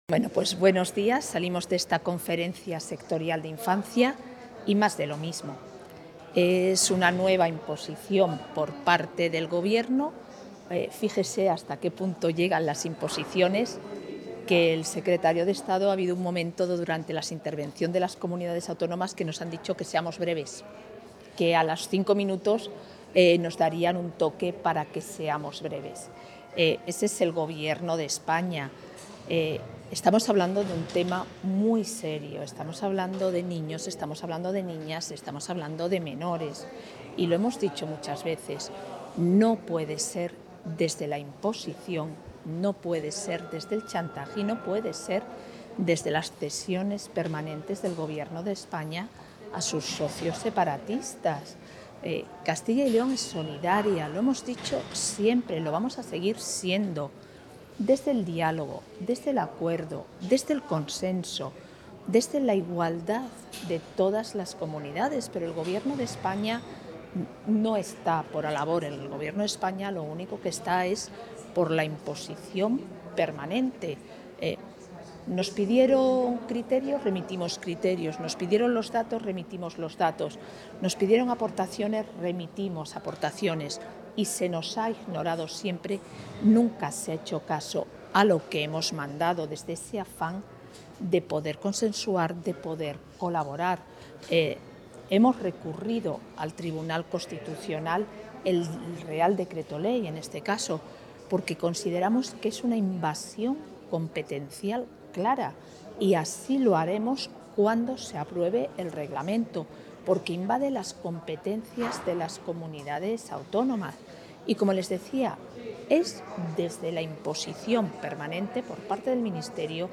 Declaraciones de la vicepresidenta de la Junta tras la Conferencia Sectorial de Infancia y Adolescencia